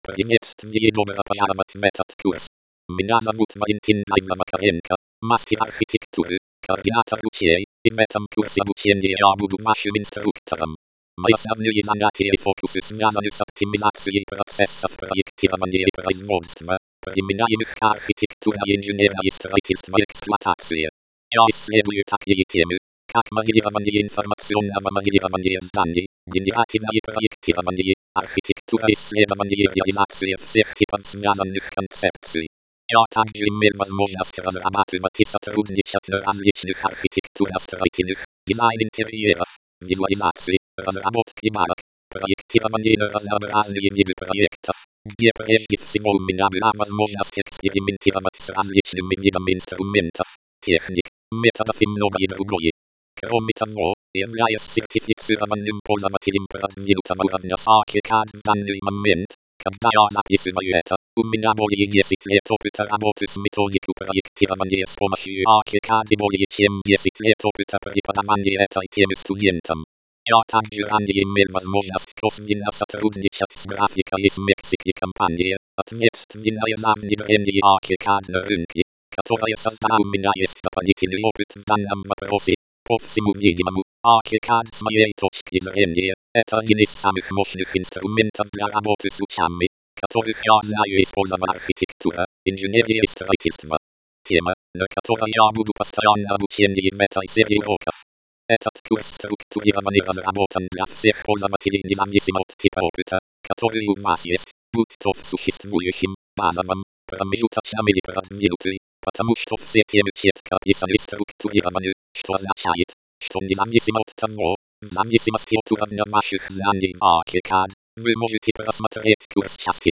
Режим: Видео + озвучка (Русский)